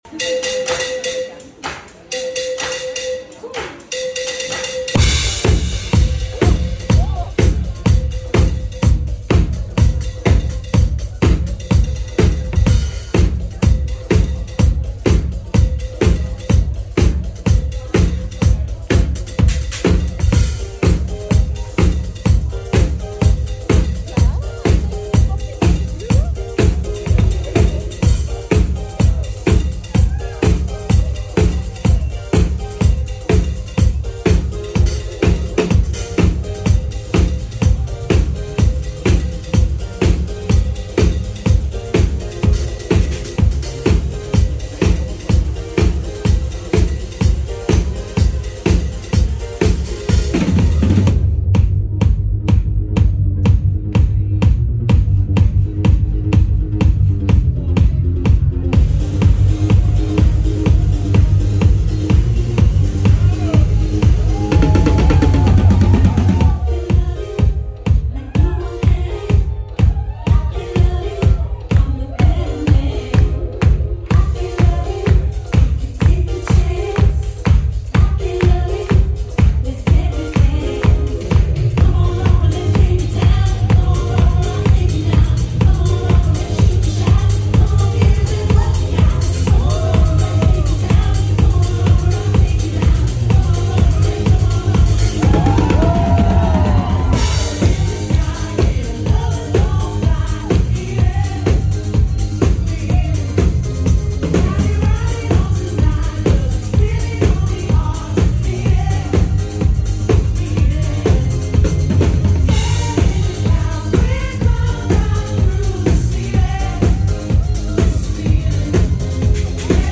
Muziekgieterji in Maastricht(29.05.2025)